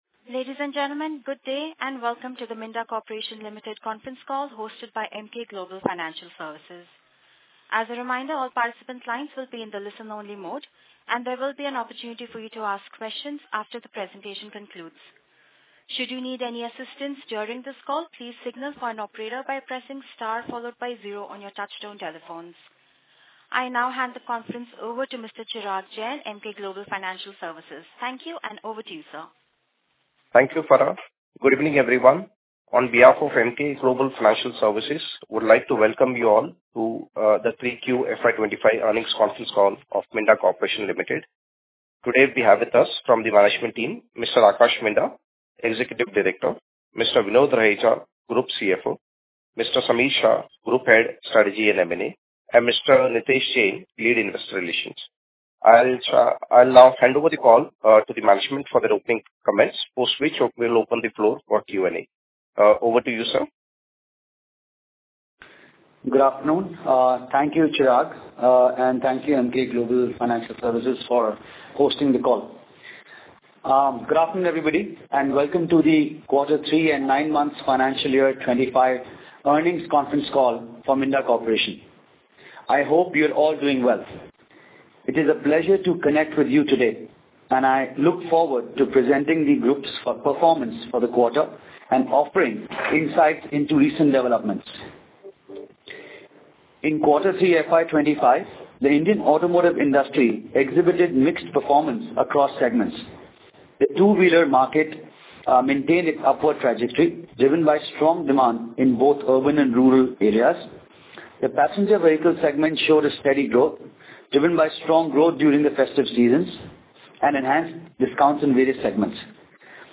Audio recording of the Earning call on financial results for quarter ended December 31, 2024